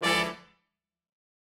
GS_HornStab-E7b2sus4.wav